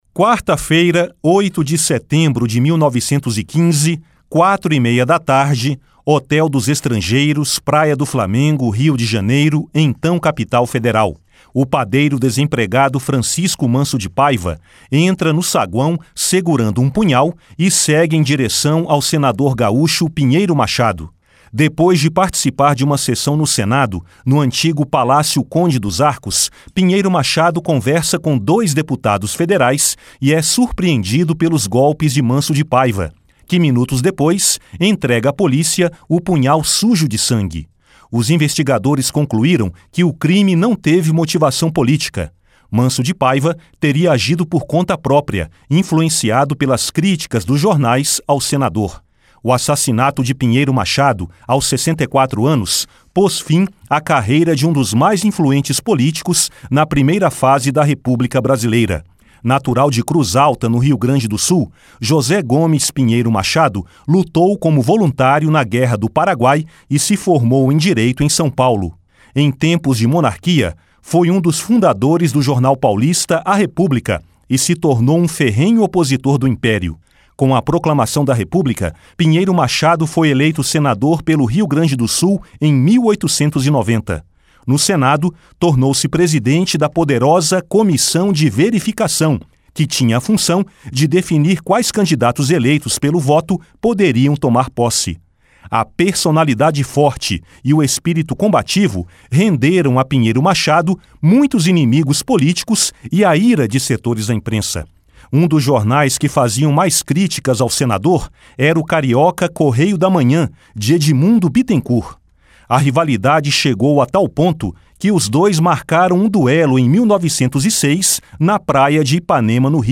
(Repórter)